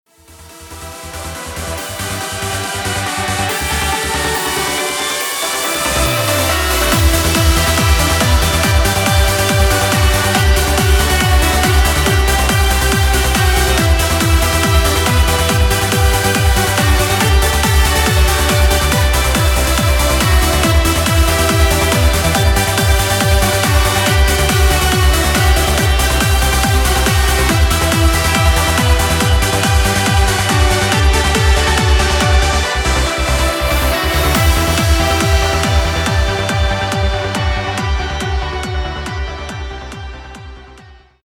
Uplifting Trance.
Хотя на начальной стадии мне очень нравилось как бочка звучала, мягко, без гула, но в то же время глубоко.
И если уж вы оставляете такие комментарии, то за сведение я спокоен Ну и собственно немного поправил бас/бочку.